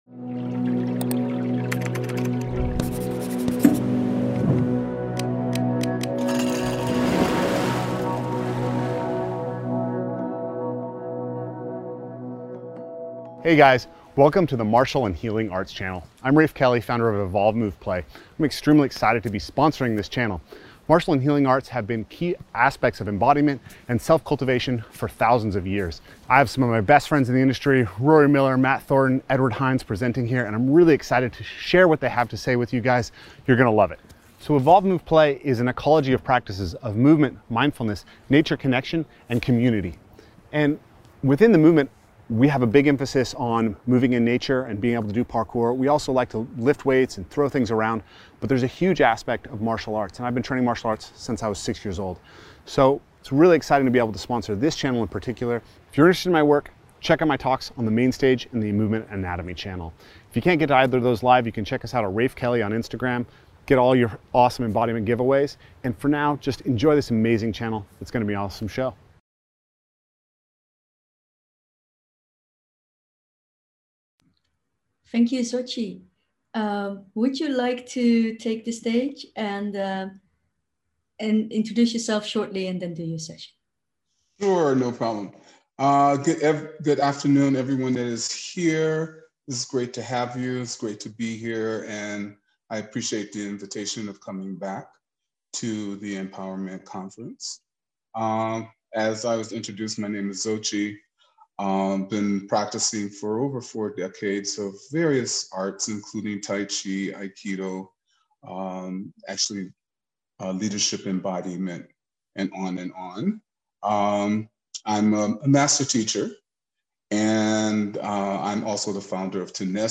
Guided Practices